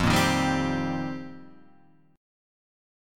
F 7th Suspended 2nd Suspended 4th